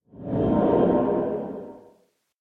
Minecraft / ambient / cave / cave12.ogg
cave12.ogg